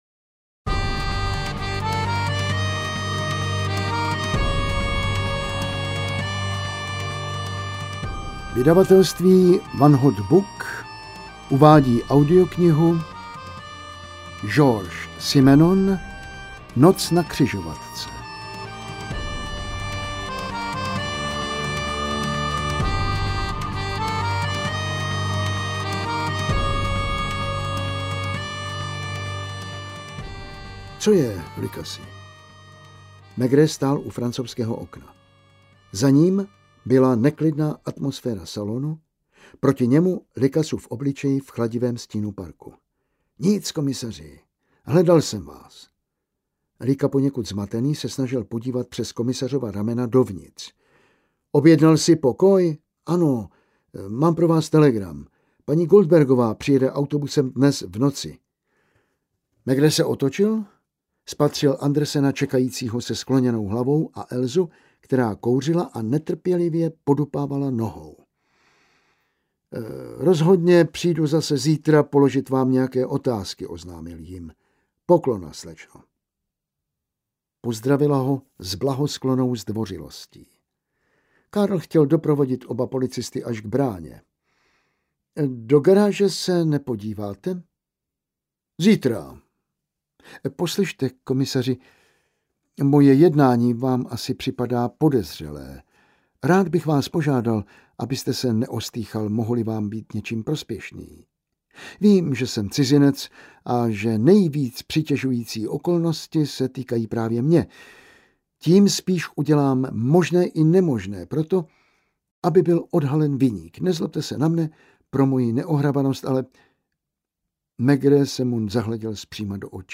Maigret: Noc na křižovatce audiokniha
Ukázka z knihy